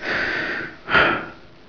gasm_breath3.wav